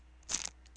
plastic_pickup.wav